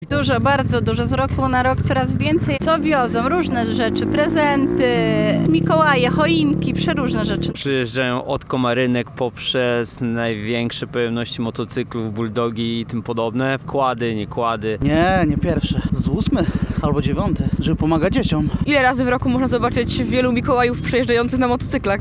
Co o paradzie do powiedzenia mieli uczestnicy i widzowie obserwujący przygotowania?